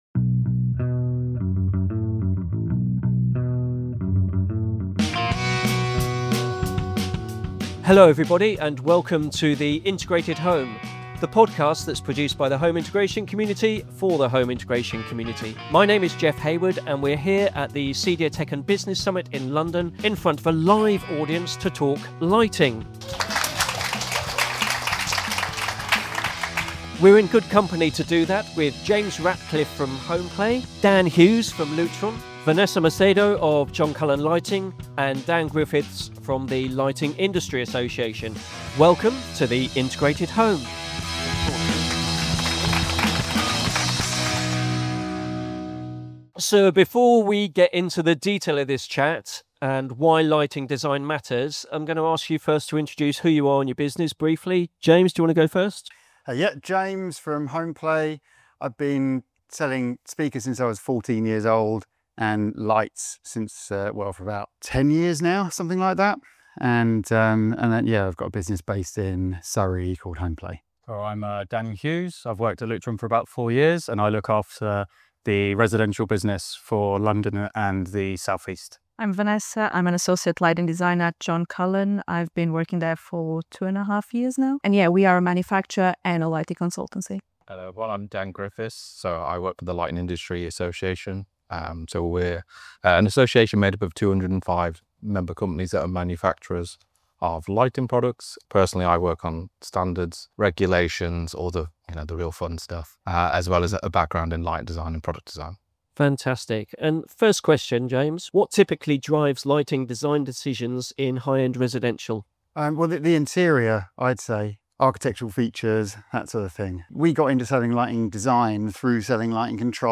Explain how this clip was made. Recorded at the CEDIA Tech + Business Summit in London.